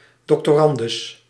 Ääntäminen
Synonyymit drs. meester Ääntäminen Tuntematon aksentti: IPA: /ˌdɔktɔˈrɑndʏs/ IPA: /ˌdɔktəˈrɑndəs/ Lyhenteet ja supistumat drs.